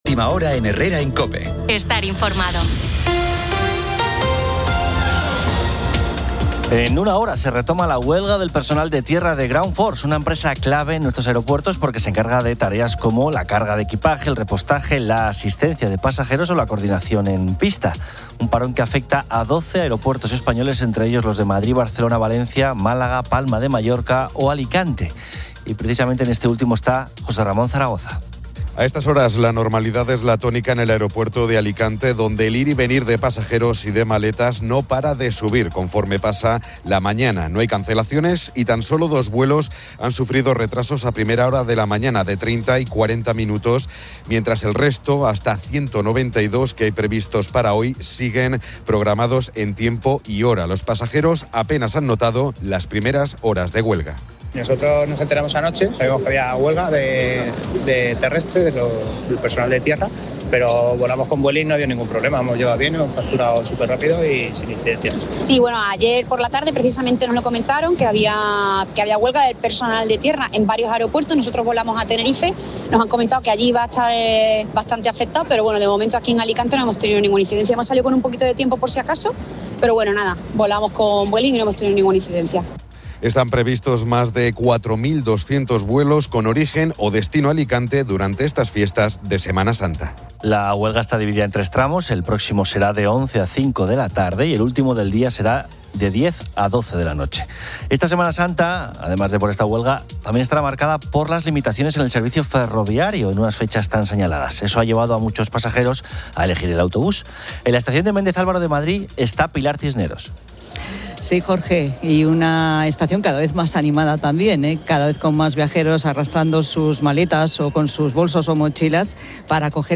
Una parte central del espacio la ocupan las experiencias compartidas por los oyentes, que relatan anécdotas de viajes marcados por imprevistos, desde vuelos desviados a Roma en lugar de Nápoles, hasta reservas de apartamentos erróneas o cancelaciones de última hora.